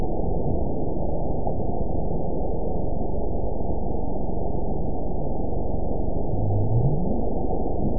event 914311 date 05/04/22 time 21:02:35 GMT (3 years ago) score 9.48 location TSS-AB05 detected by nrw target species NRW annotations +NRW Spectrogram: Frequency (kHz) vs. Time (s) audio not available .wav